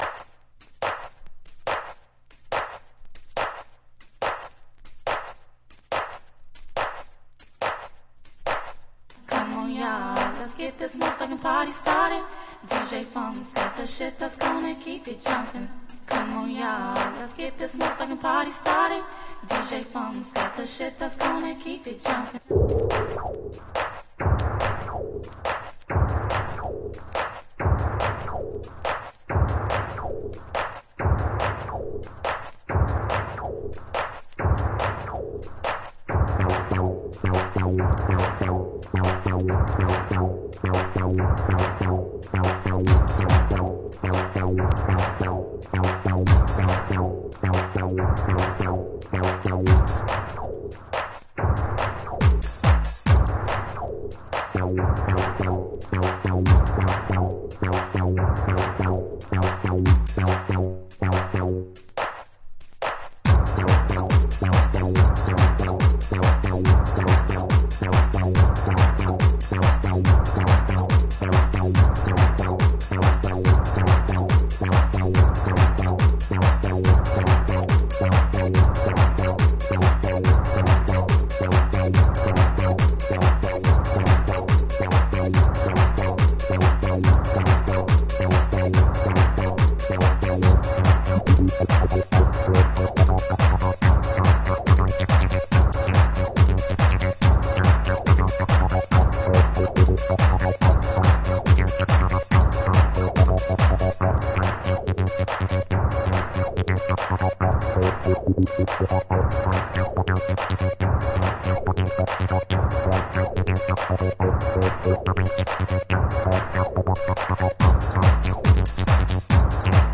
兎にも角にもバウンスバウンス。